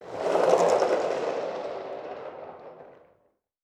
Skateboard Wheels Fast Speed.wav